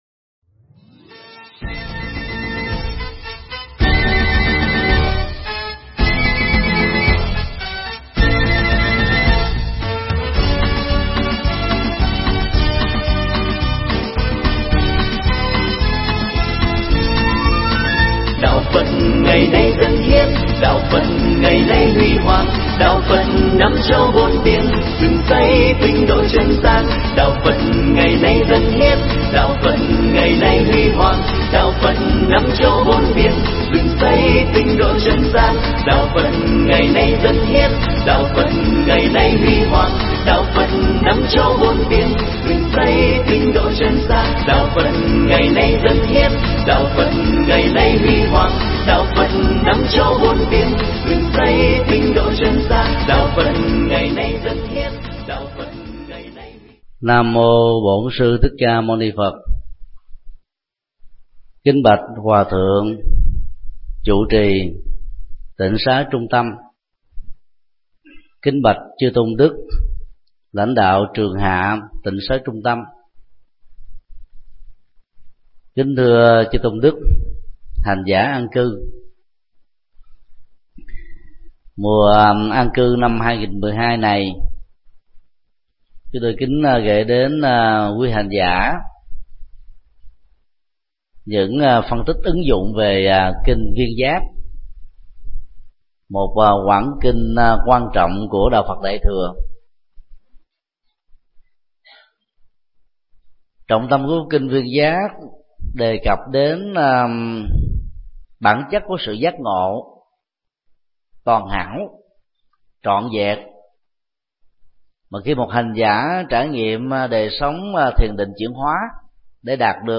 Mp3 Pháp thoại Kinh Viên Giác 01: Chuyển hóa vô minh để sống hạnh phúc hơn do TT. Thích Nhật Từ giảng tại trường hạ Tịnh xá Trung Tâm, ngày 15 tháng 06 năm 2012